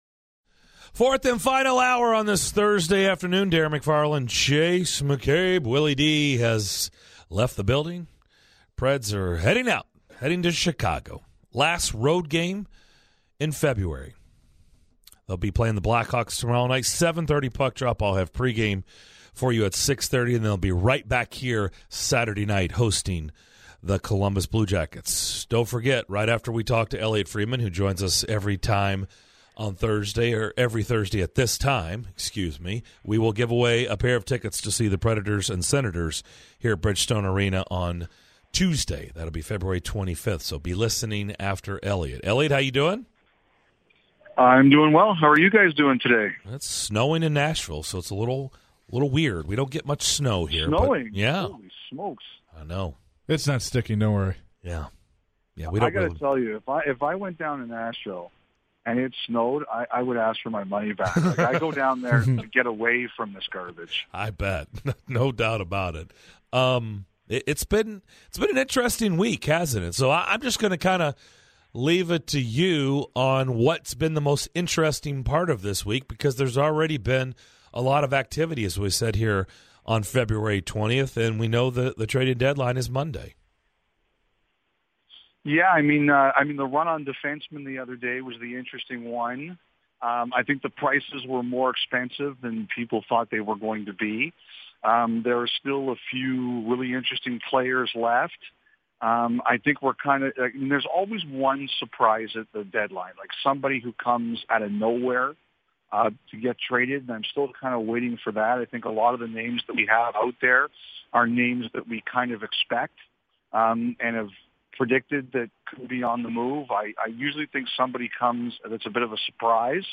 The guys are joined by Eliotte Friedman with Sportsnet. He discusses theoretically the job security of some of the Preds players for next season. Also the possibility of Shea Weber's 1,000th game to be in Nashville on April 1st.